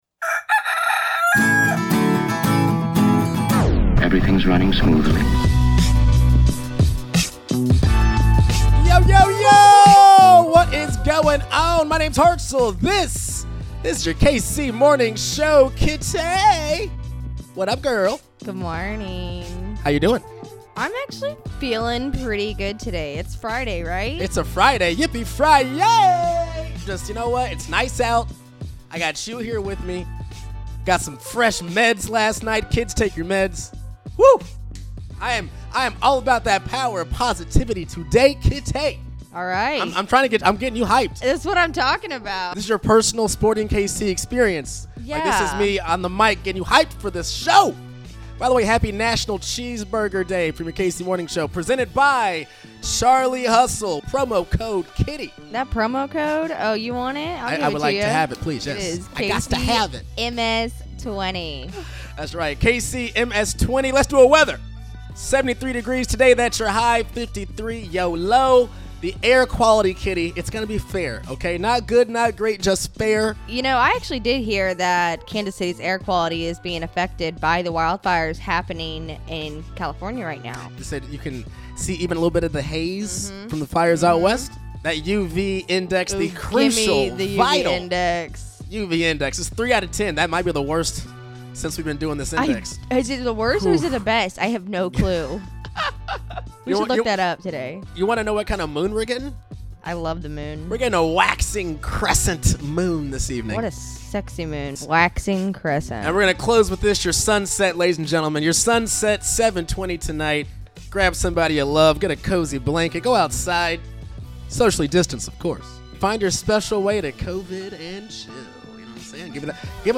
we wrap up part 2 of our interview with Mayor Q.